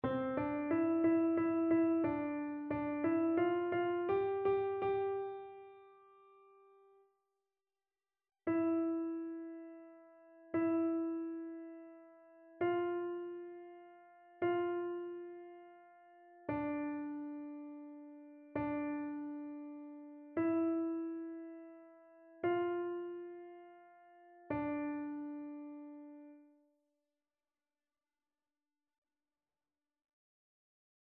AltoTénorBasse
annee-c-temps-ordinaire-2e-dimanche-psaume-95-alto.mp3